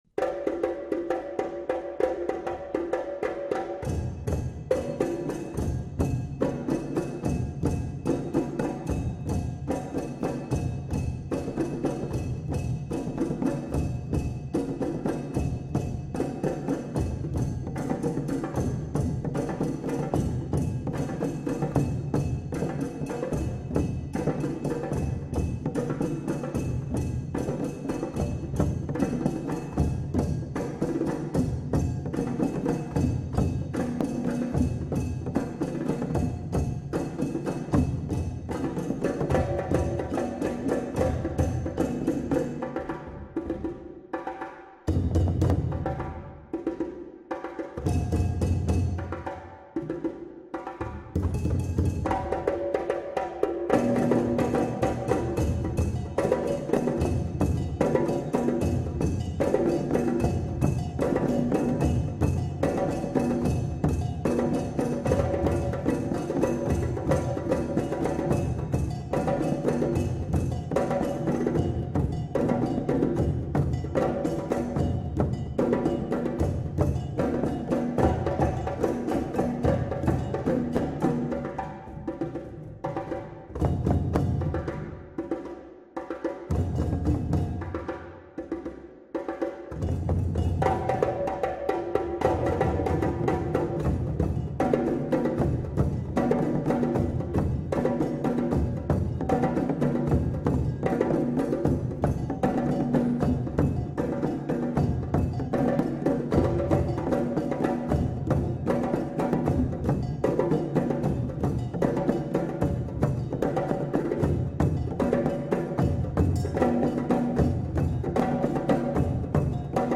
DrumLove slower, Tripple Time 8 Nov 2012